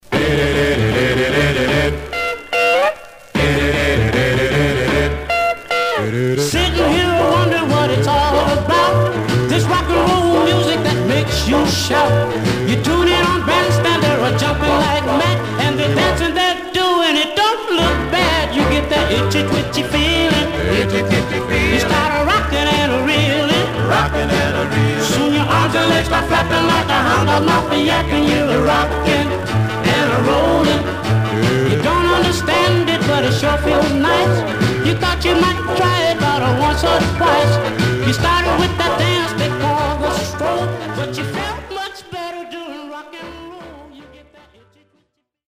Some surface noise/wear
Mono
Rythm and Blues